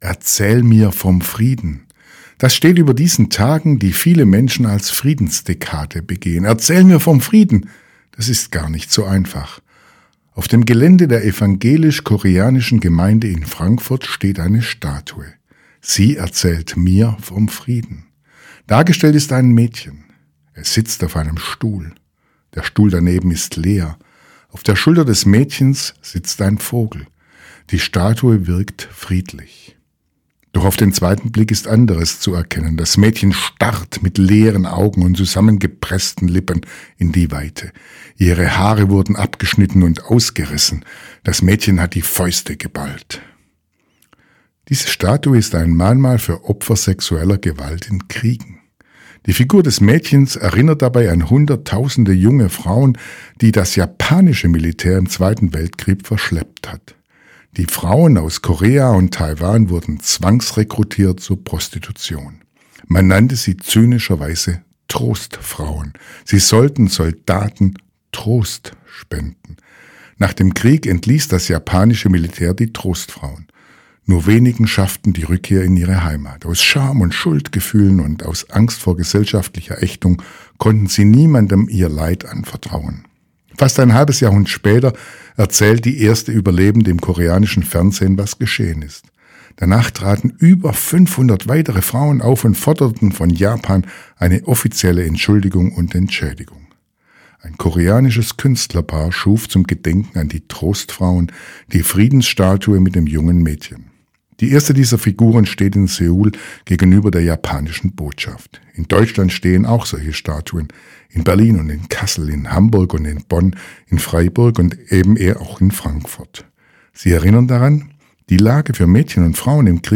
Radioandacht vom 12. November